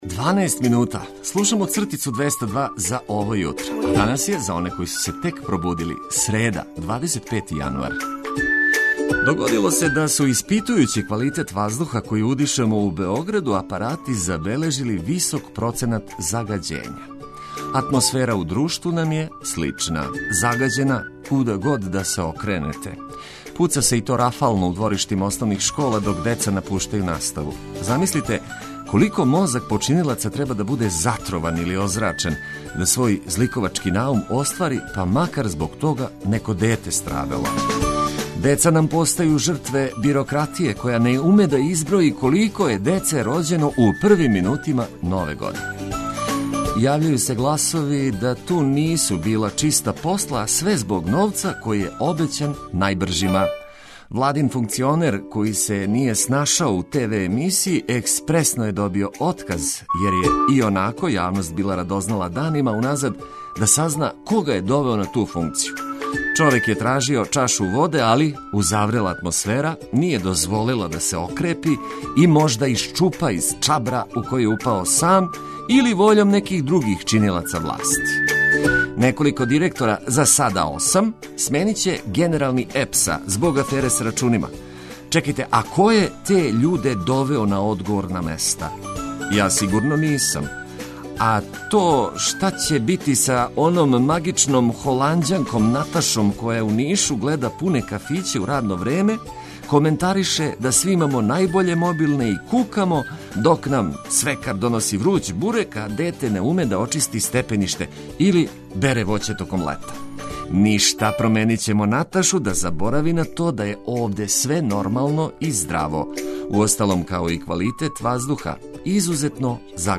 Најважније информације од користи за све који нас слушају уз брзи ритам наjбоље музике за лакше буђење.